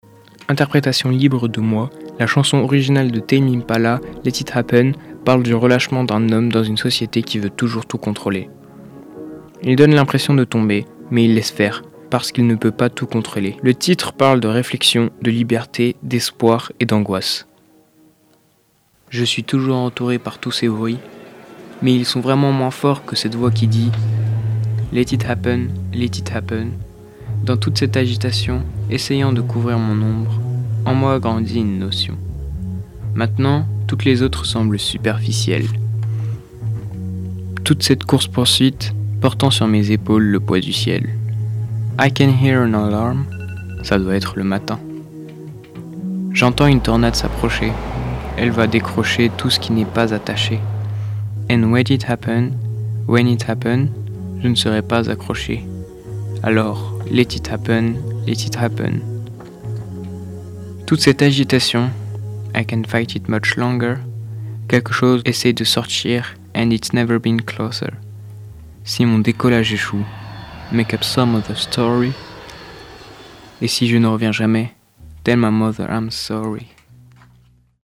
Interprétation libre.